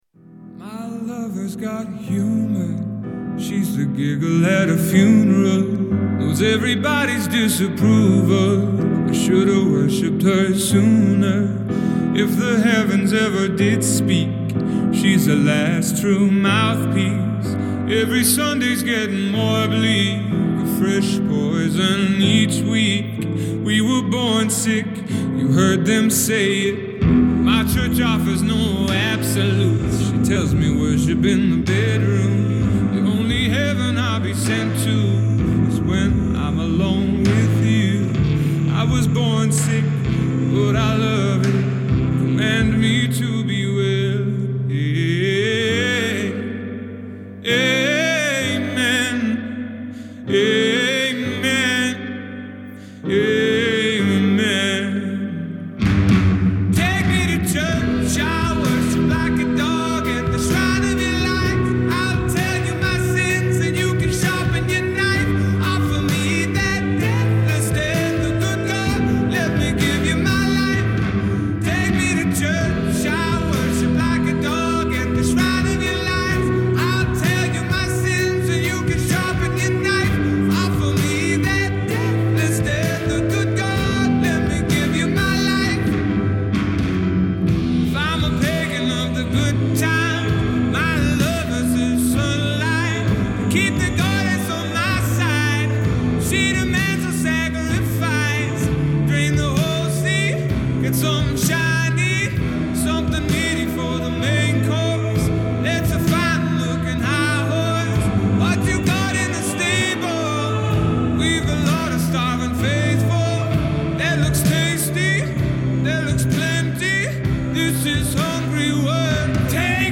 خواننده-ترانه‌سرای ایرلندی
یک قطعهٔ مید-تمپو سول